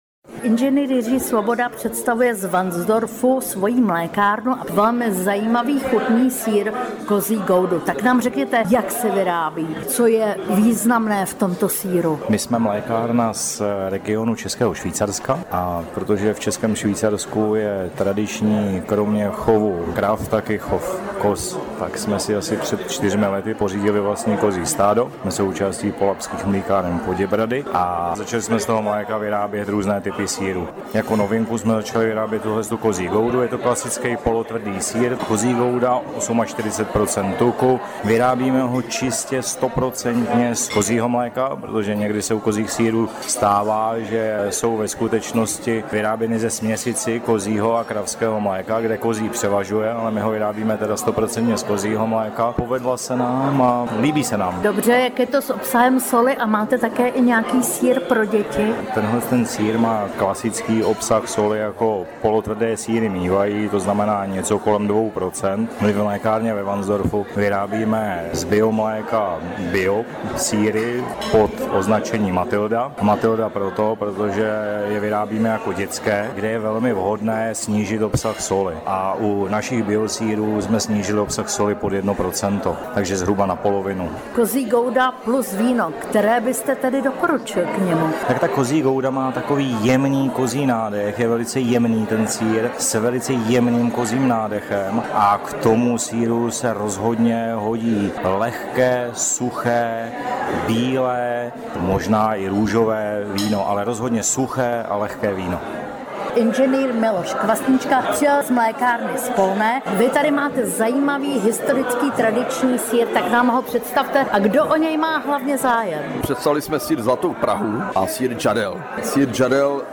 Rozhovor o sýrech se zástupci českých mlékáren.